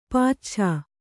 ♪ pacchā